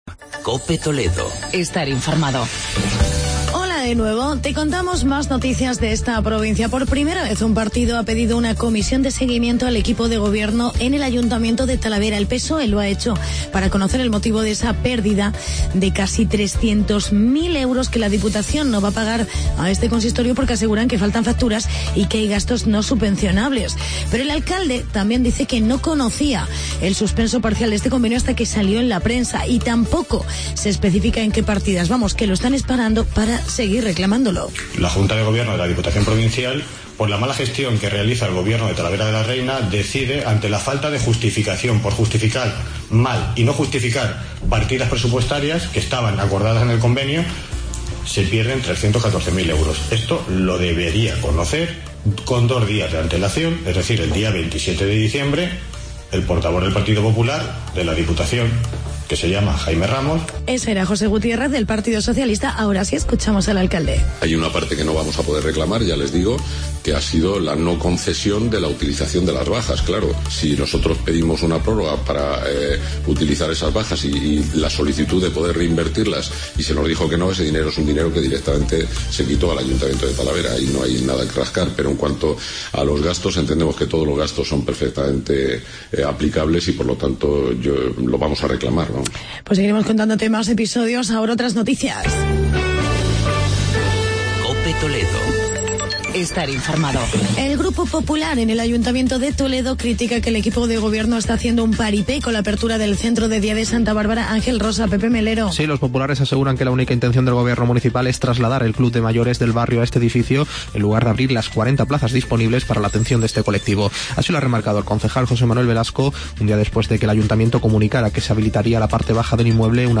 Actualidad y entrevista con José Luis Muelas, alcalde de Gamonal por la fiesta de la Candelaria.